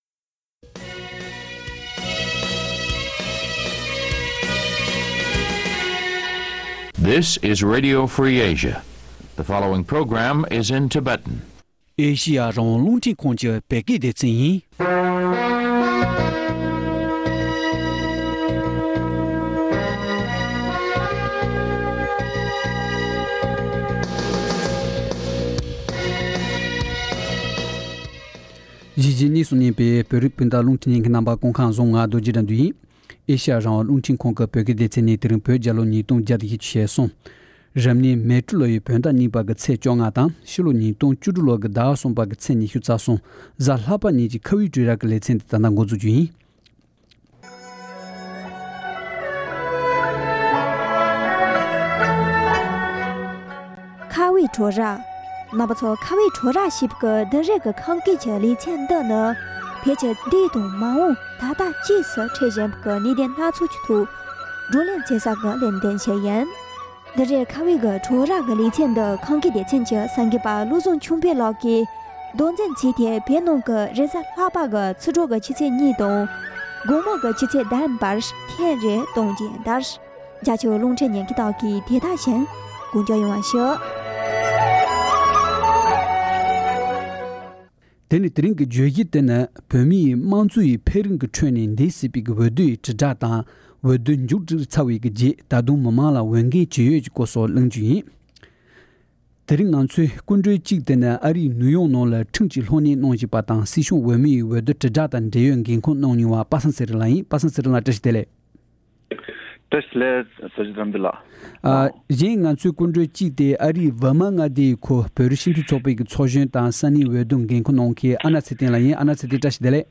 གླིང་མོལ་བྱེད་པ་ཉན་རོགས་ཞུ།